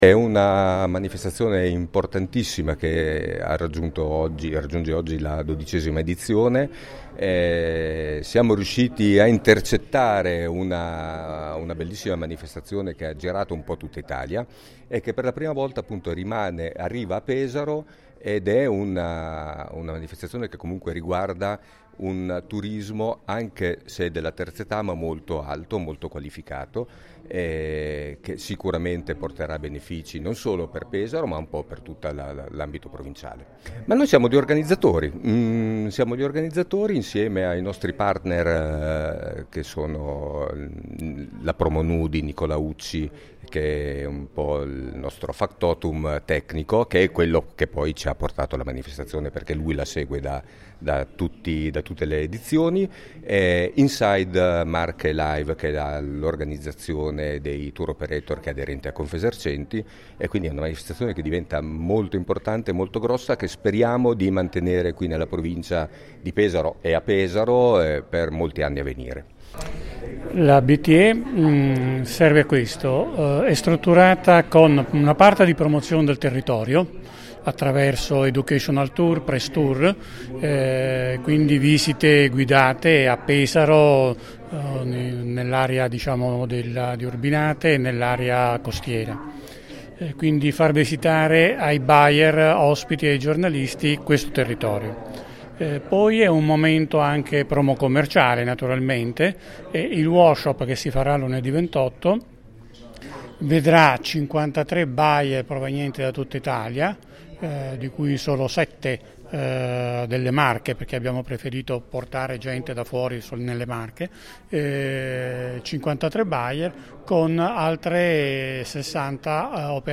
Ai nostri microfoni: